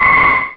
Cri de Kirlia dans Pokémon Rubis et Saphir.